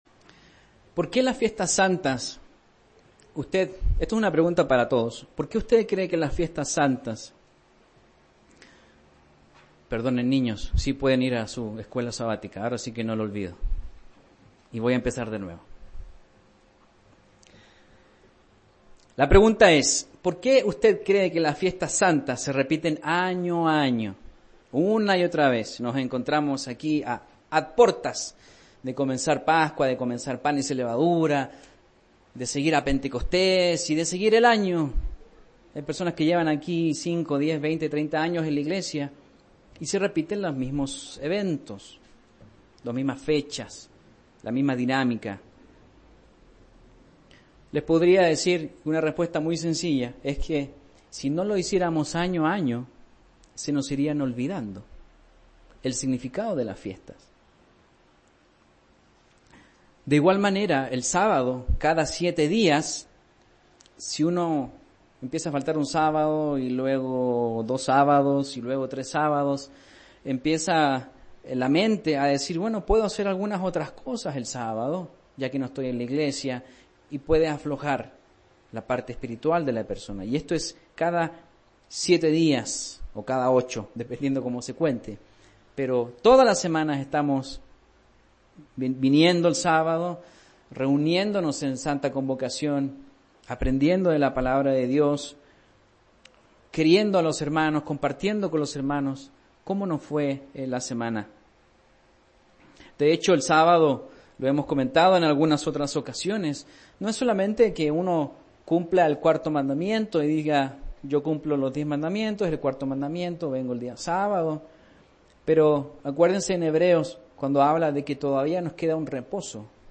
En la Biblia se describen dos cenas distintas celebradas en el Antiguo Testamento: La cena de Pascua el 14 de Nisan y la Noche de Guardar, que inaugura la fiesta de Panes Ácimos el 15 de Nisan. Mensaje entregado el 24 de marzo de 2018.